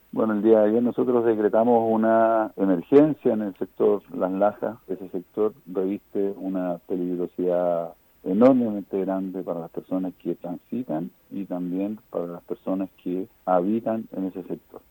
Así lo confirmó el director regional de Senapred, Daniel Epprecht, señalando que el terreno reviste una peligrosidad enorme para las personas que habitan o transitan a diario por el sector.